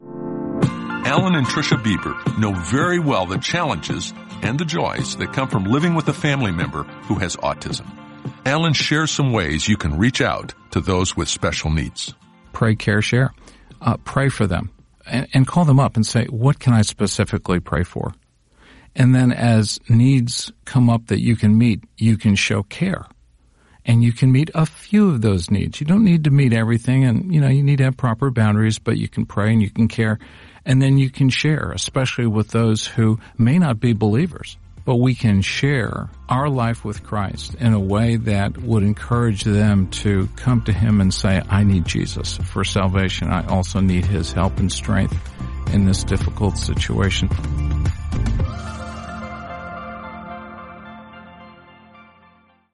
Each of these five devotionals contains a short audio message (1-2 minutes).